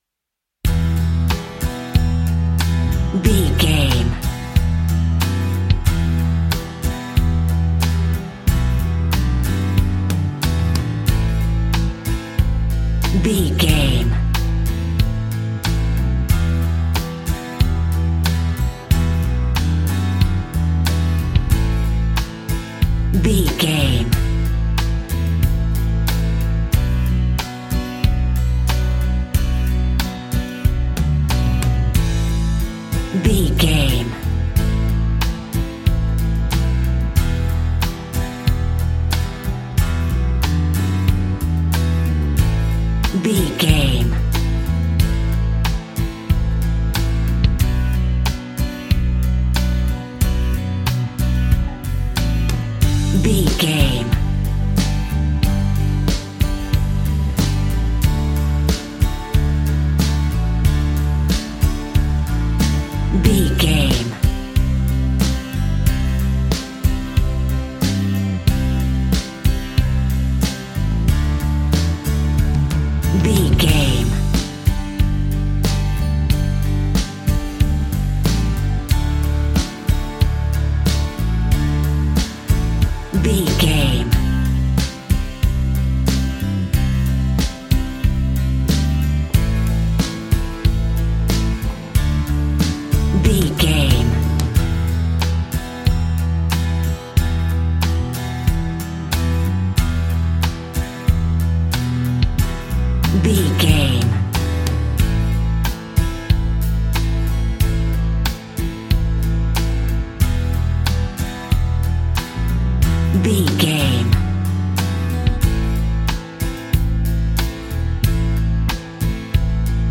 Ionian/Major
romantic
sweet
happy
acoustic guitar
bass guitar
drums